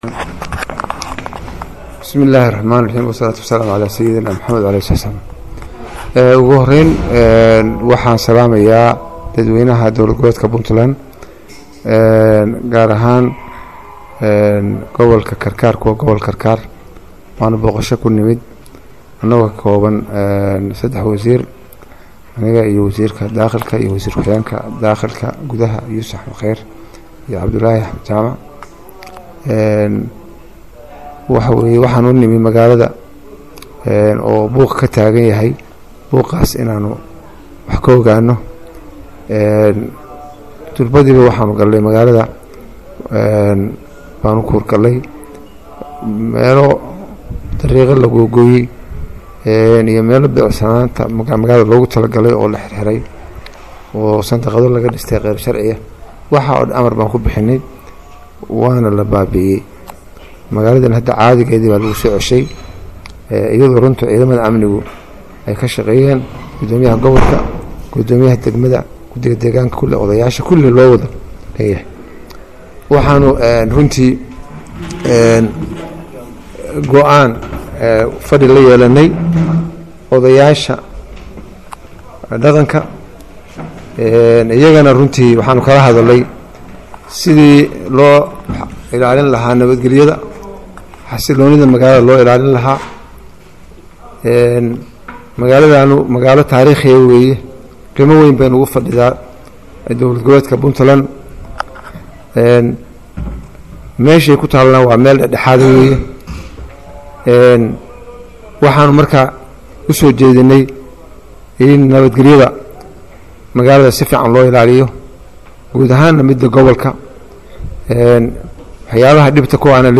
Codka Wasiirka Amaanka Puntland Cabdulaahi Siciid Samatar(Maqal) Dhagayso